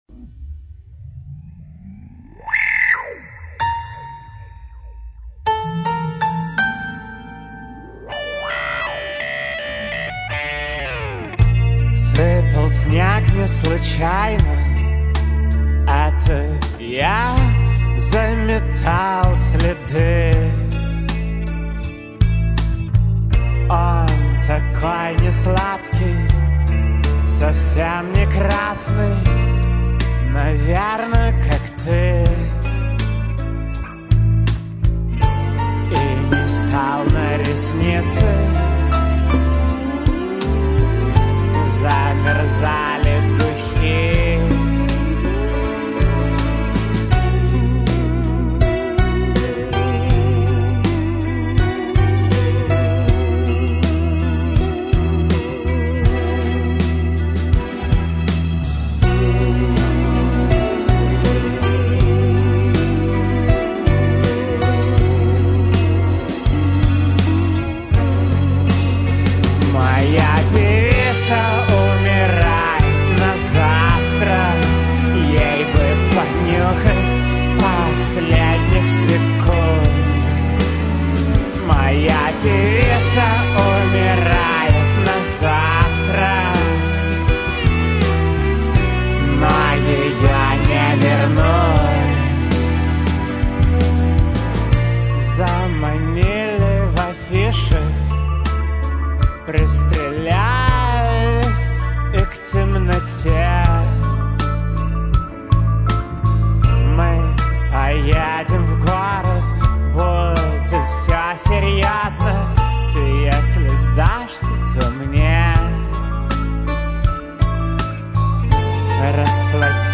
- вокал,  клавишные
- бас,  клавишные
- ударные,  программирование
- гитары,  клавишные